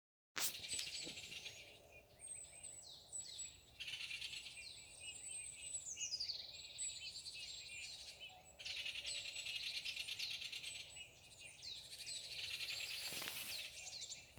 Great Spotted Woodpecker, Dendrocopos major
Administratīvā teritorijaVentspils novads
StatusAgitated behaviour or anxiety calls from adults